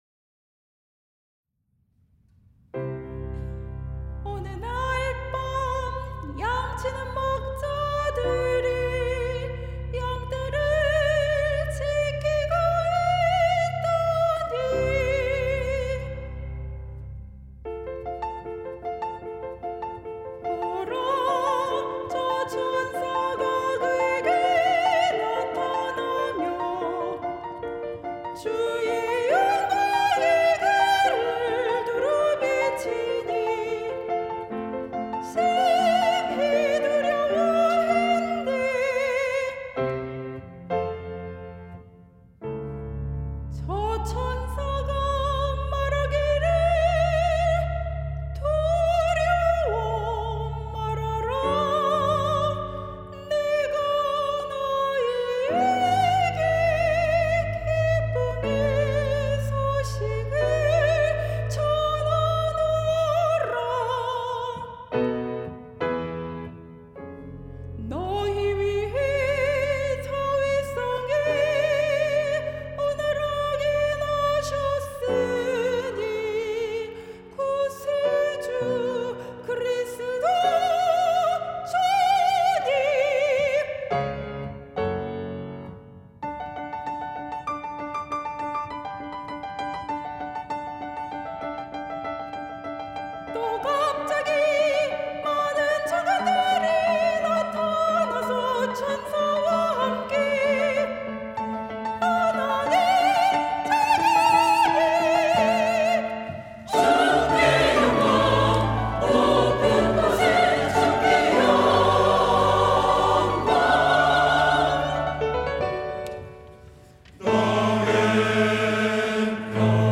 시온(주일1부) - 주께 영광
찬양대